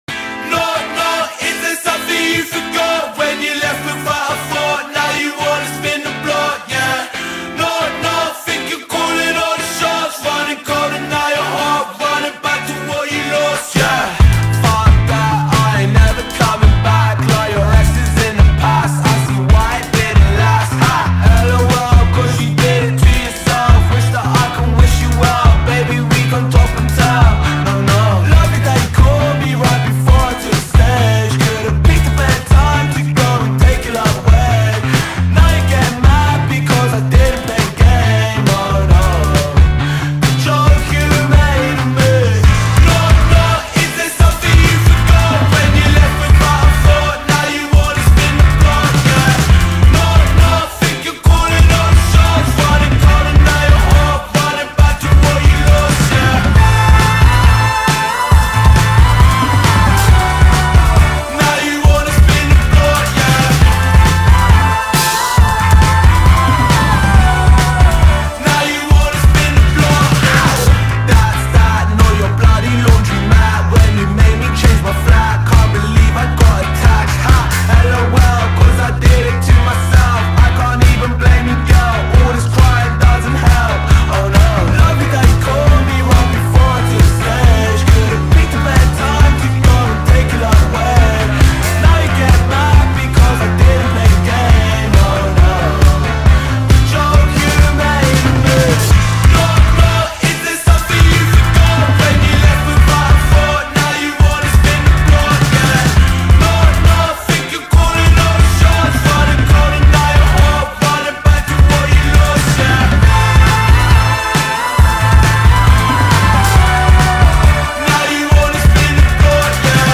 BPM136
Audio QualityPerfect (High Quality)